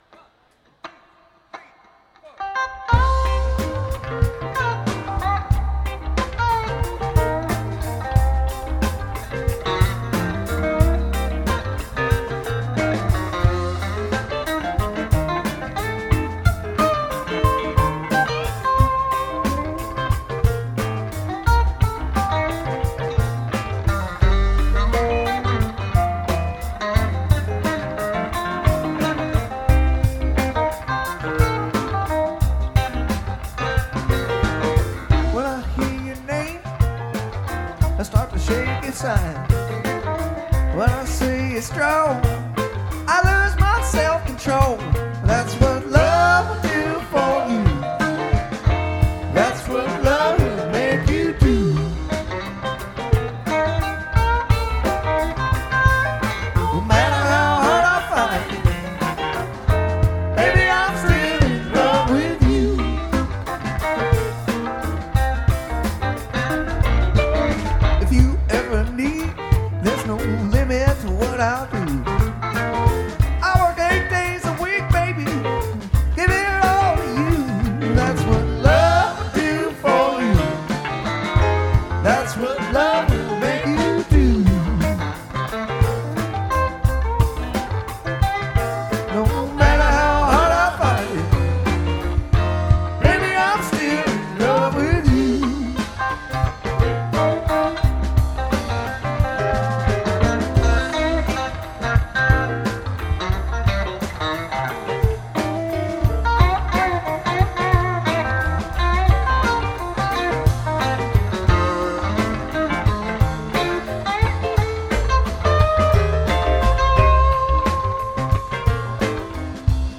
Encore & Soundcheck